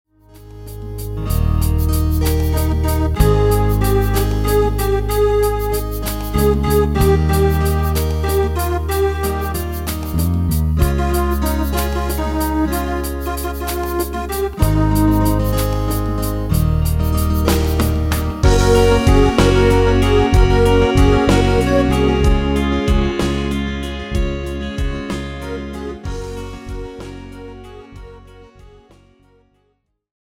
Žánr: Rock
MP3 ukázka s ML